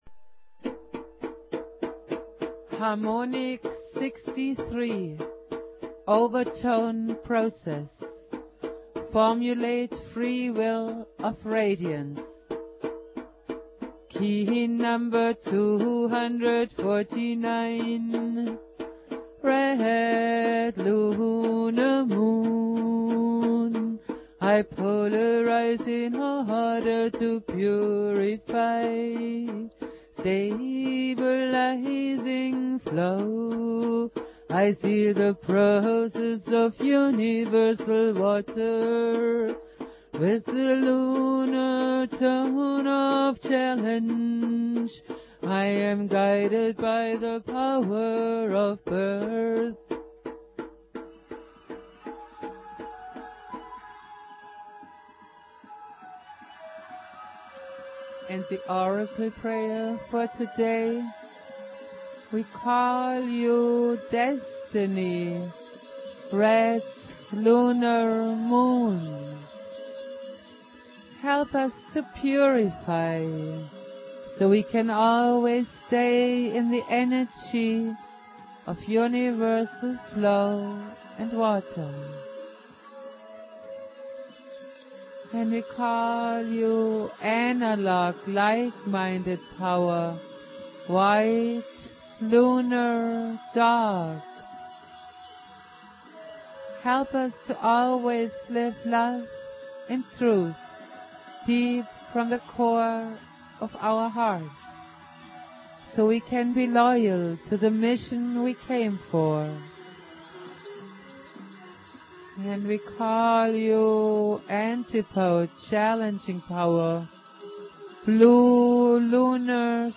Valum Votan playing flute.
Prayer
produced at High Flowing Recording Studio
Jose's spirit and teachings go on Jose Argüelles playing flute.